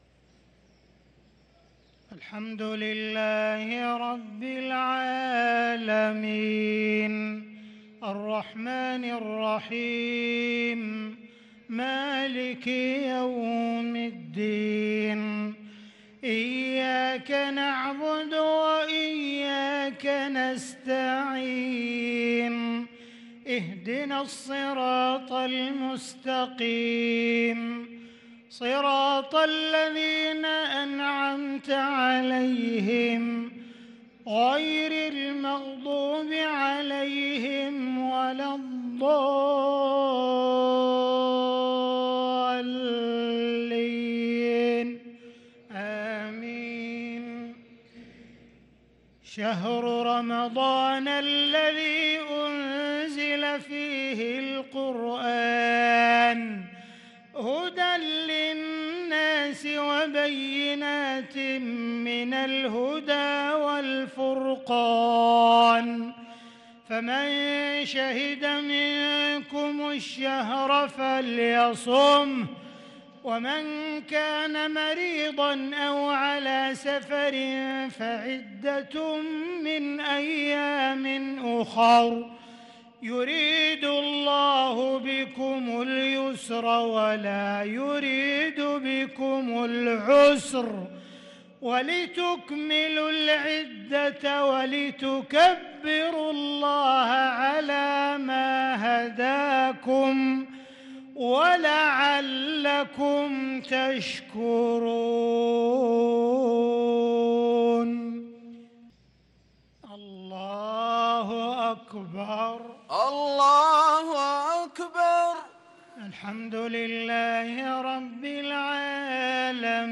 صلاة المغرب للقارئ عبدالرحمن السديس 1 رمضان 1443 هـ
تِلَاوَات الْحَرَمَيْن .